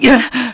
Theo-Vox_Damage_kr_01.wav